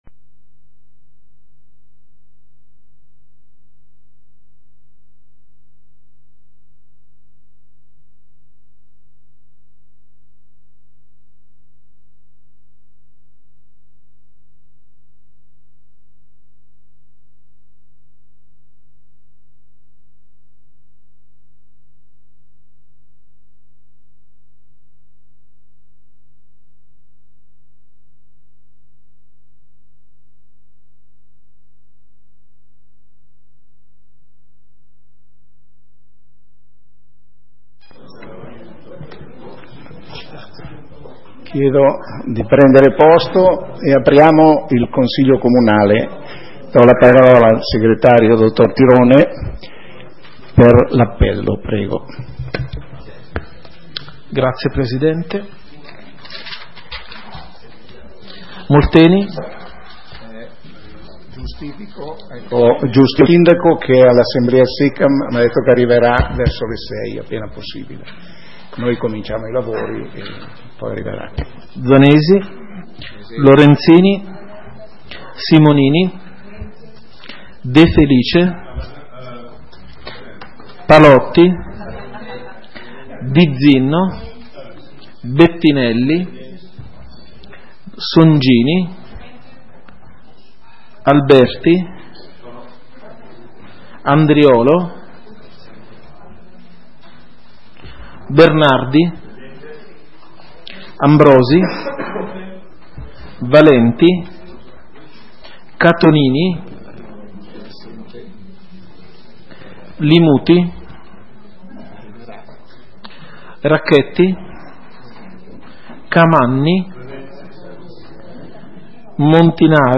Seduta consiglio comunale del 28 aprile 2017 - Comune di Sondrio
Ordine del giorno ed audio della seduta consiliare del Comune di Sondrio effettuata nella data sotto indicata.